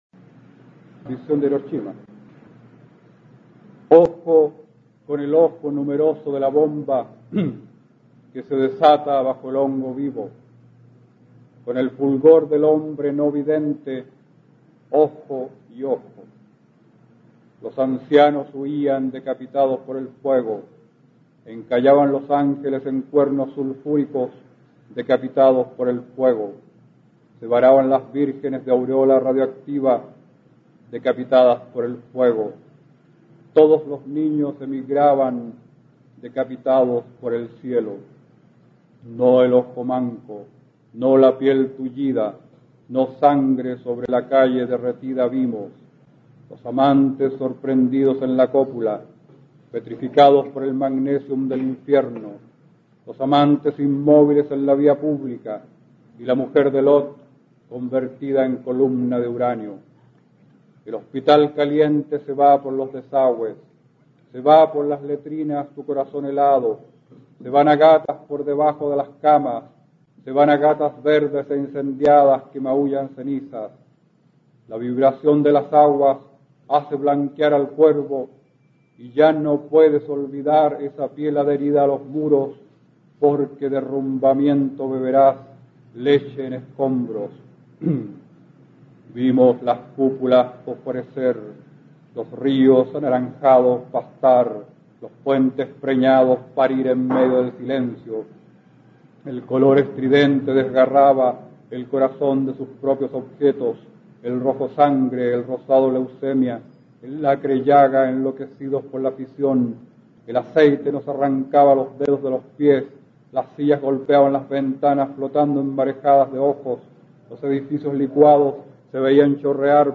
Aquí puedes escuchar al poeta chileno Óscar Hahn, perteneciente a la Generación del 60, recitando su poema Visión de Hiroshima, del libro "Imágenes nucleares" (1983).